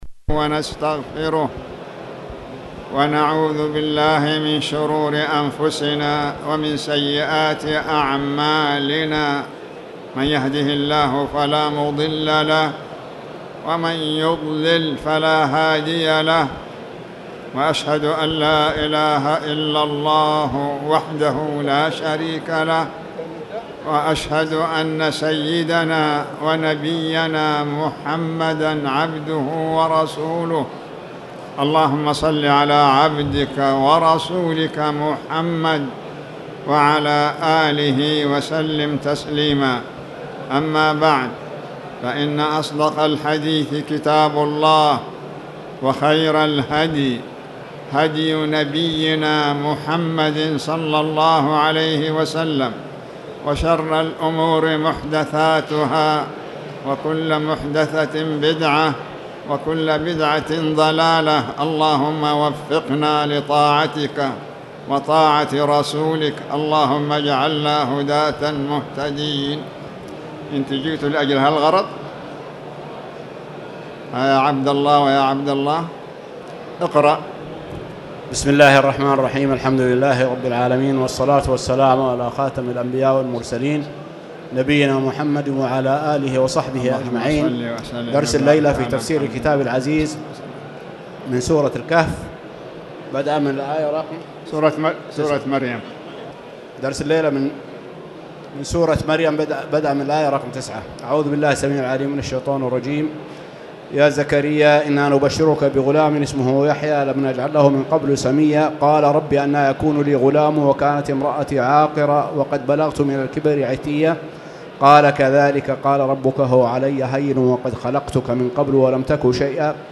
تاريخ النشر ١٣ ربيع الأول ١٤٣٨ هـ المكان: المسجد الحرام الشيخ